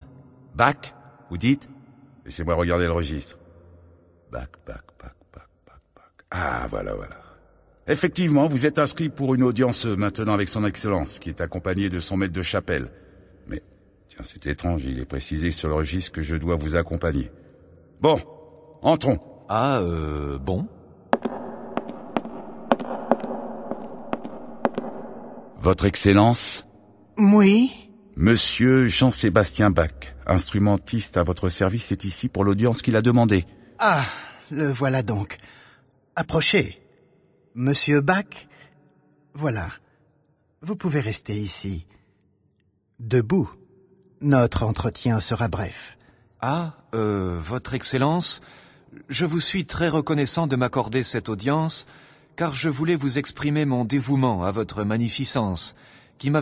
Diffusion distribution ebook et livre audio - Catalogue livres numériques
Ce disque est une biographie pour vous faire mieux connaître et aimer ce compositeur passionné, auteur de tant de chef d’oeuvres, dont la vie, tragique, intense, faite de travail acharné et de créations innombrables, reste méconnue. Le récit est émaillé d’une dizaine d'extraits des oeuvres de Bach : La Passion selon Saint Matthieu, Toccata et Fugue en Ré mineur, les Variations Goldberg, concertos brandebourgeois, l’Offrande musicale, oeuvres pour orgues, etc